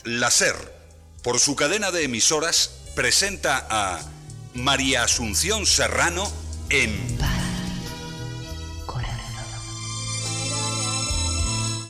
Careta del serial radiofònic.
Ficció